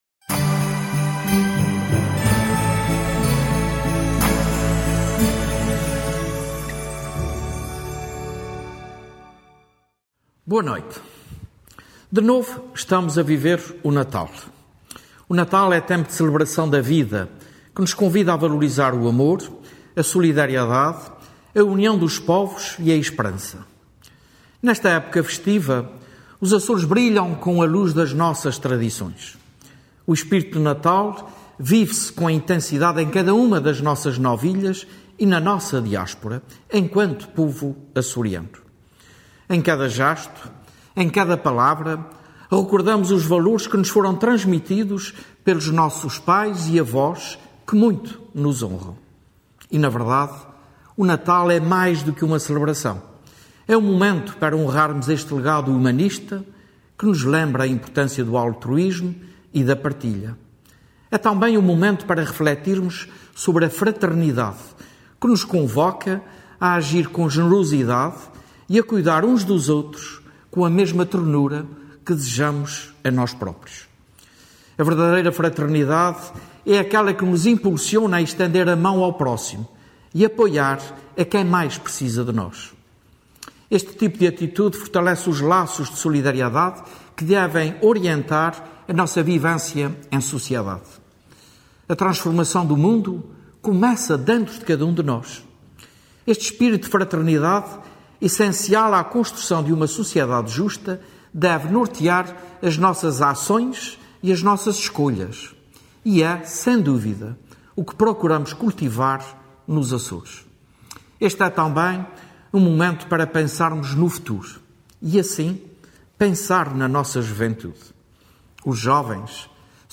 Mensagem de Natal do Presidente do Governo Regional dos Açores